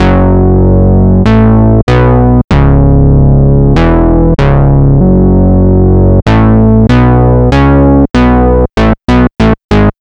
Track 14 - Synth Bass 01.wav